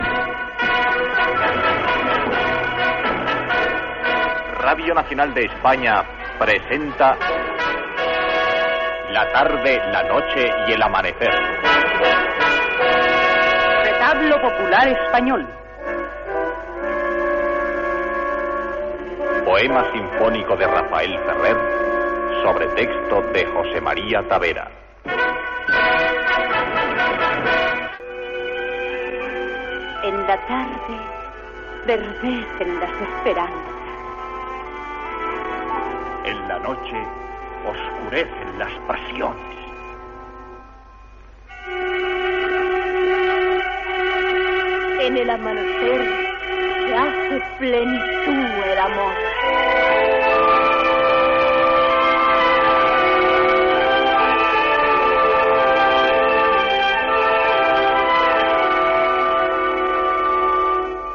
Careta de "La tarde, la noche y el amanecer. Retablo popular español" i fragment de l'obra.
Ficció
Era un poema simfònic composat per Rafael Ferrer i amb textos de José María Tavera. L'orquestra de cambra era la de RNE a Barcelona.